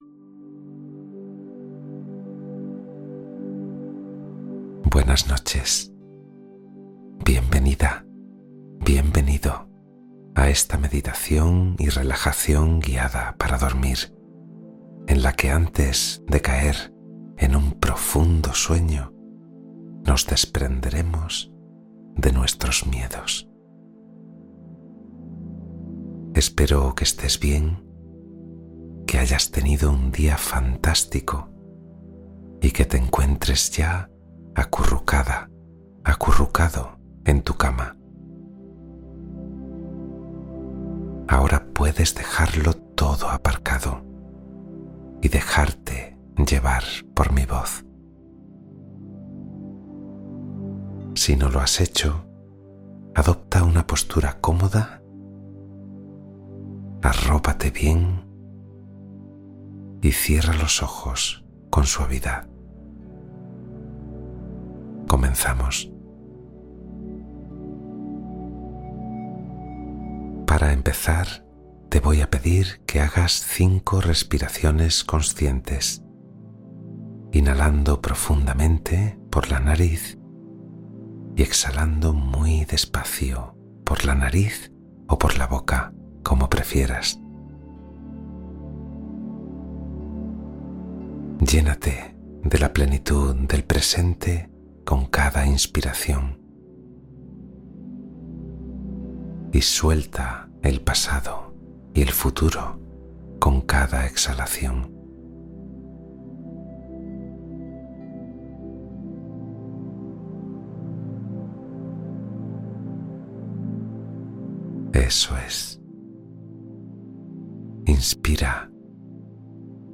Meditación guiada para dormir profundamente y liberar miedos